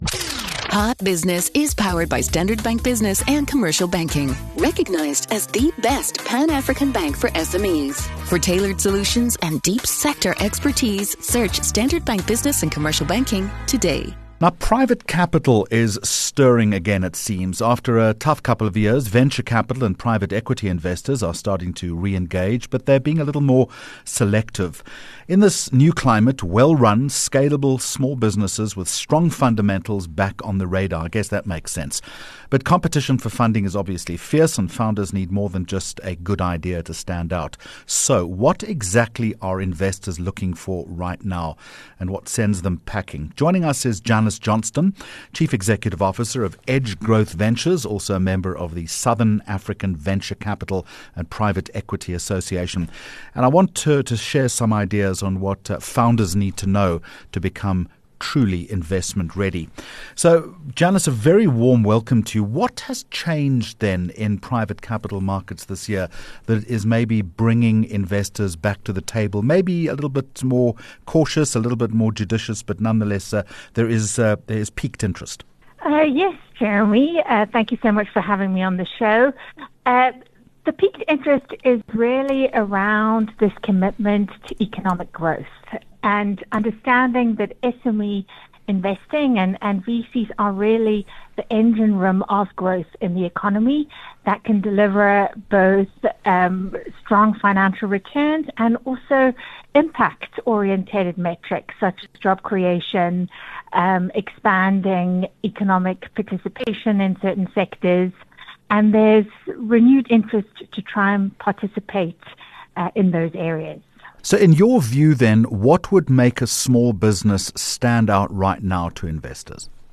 3 Jun Hot Business Interview